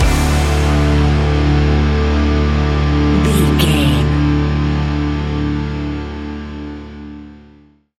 Ionian/Major
D♭
hard rock
heavy metal
instrumentals